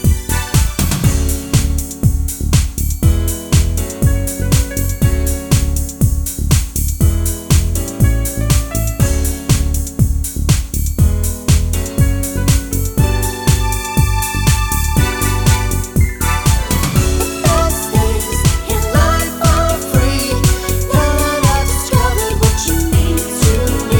no Backing Vocals Duets 4:01 Buy £1.50